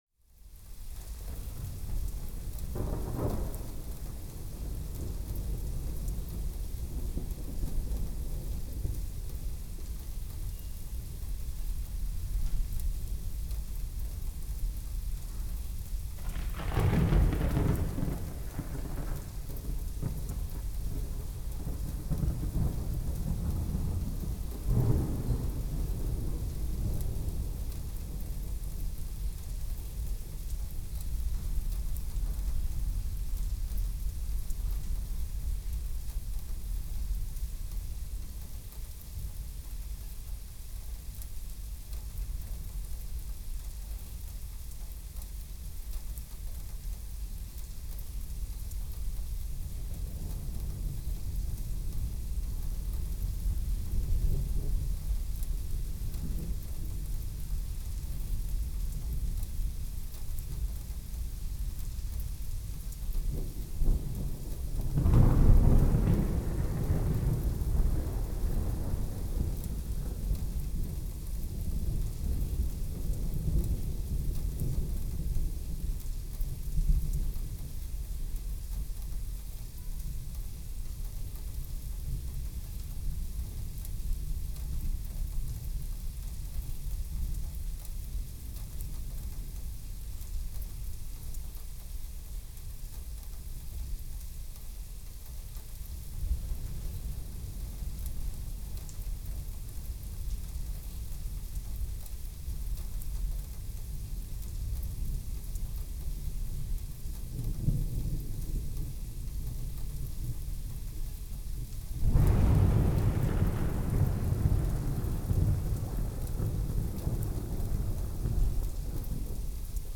copyparty md/au/ambient/Deezer/Deezer - Bruits d'orage anti-stress